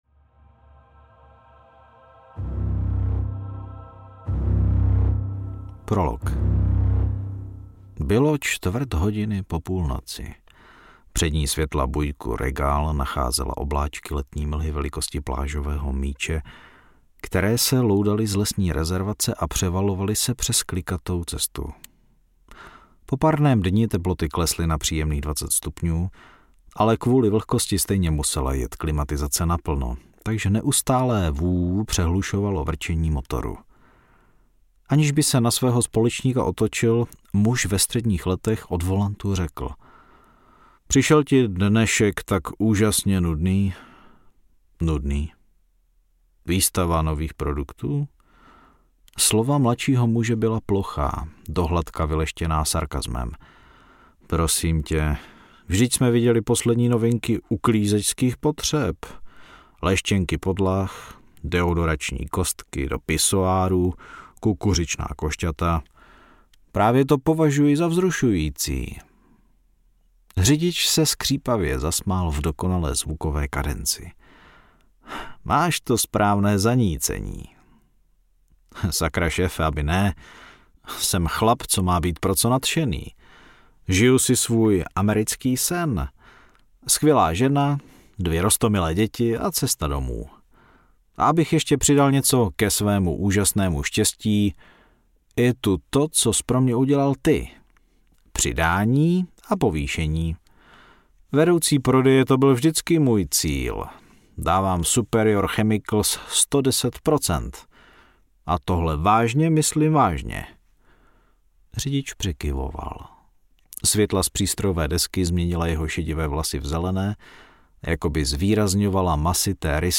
Cizinci audiokniha
Ukázka z knihy